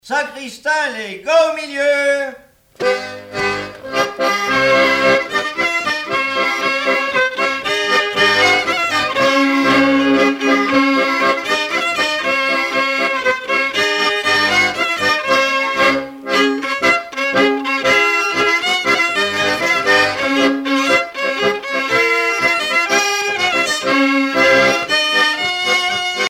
danse : sacristain
Pièce musicale éditée